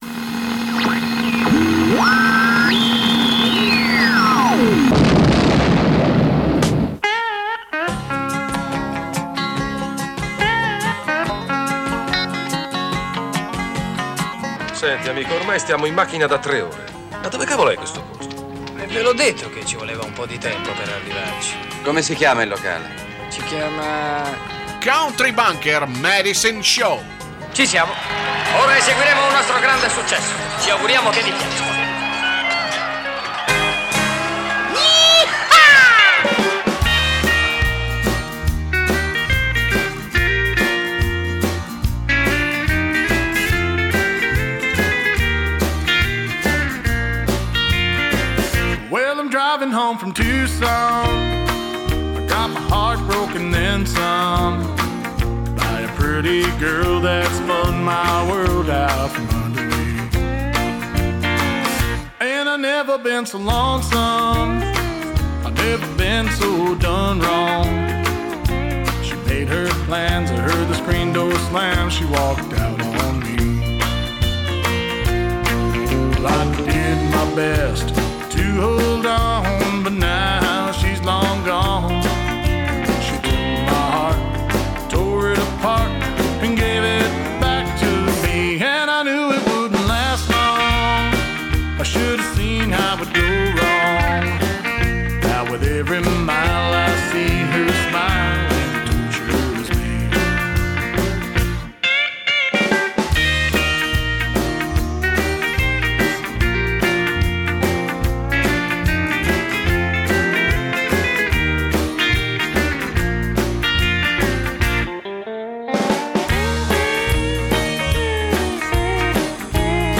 Both Kinds of Music: Country & Western